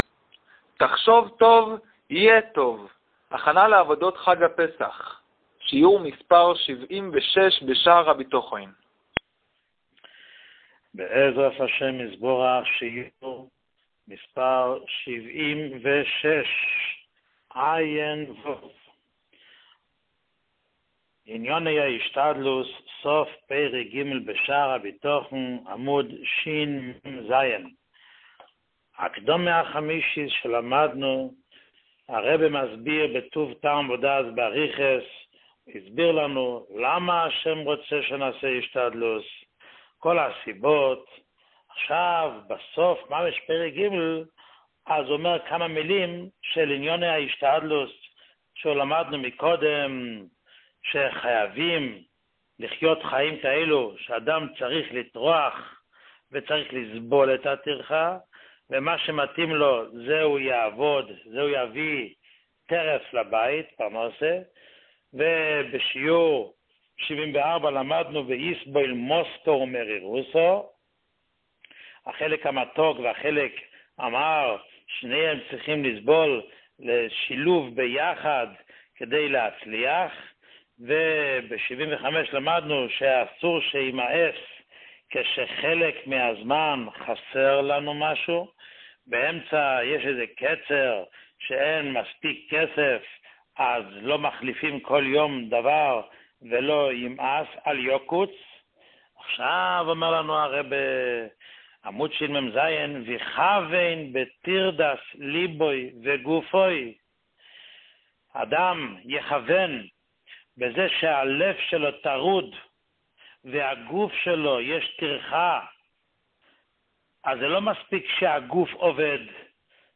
שיעור 76